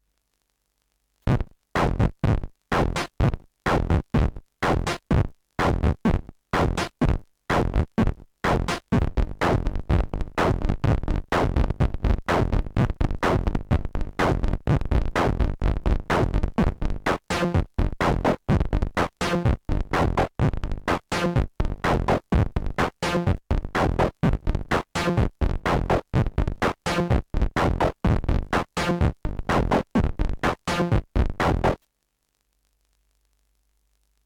rytmijuttua.ogg